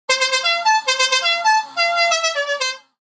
La Cucaracha Horn Sound Effect